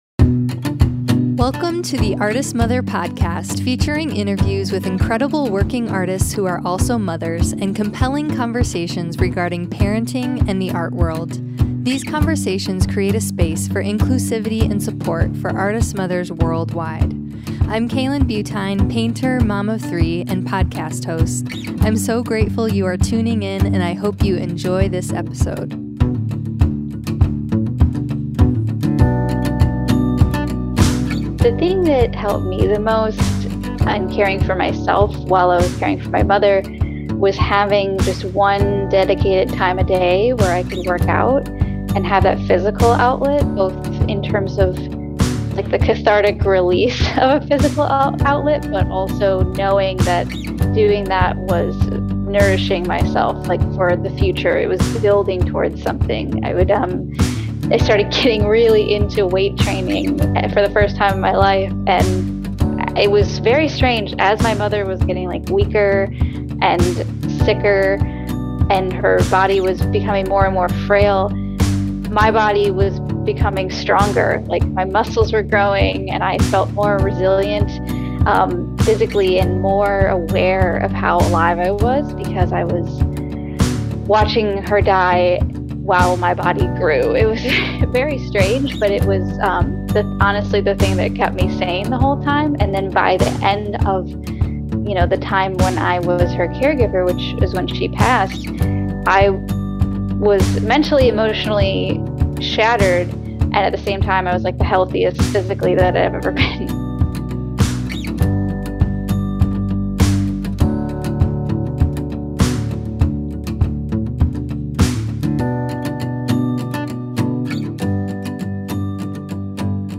A Conversation on Art, Anxiety and Mental Health